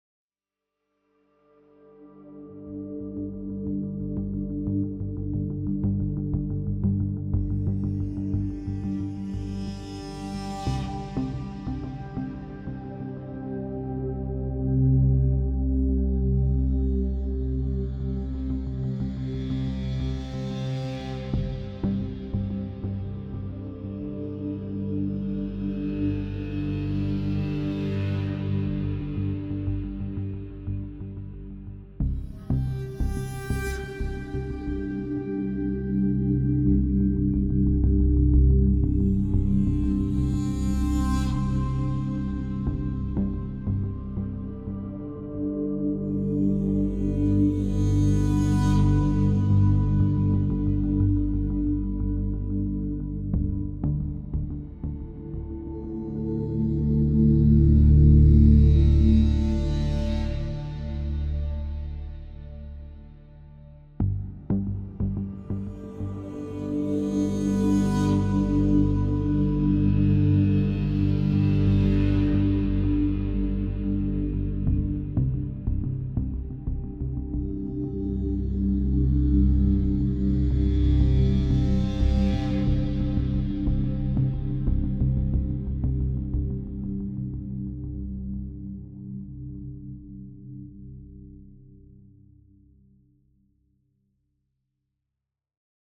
Added Ambient music pack.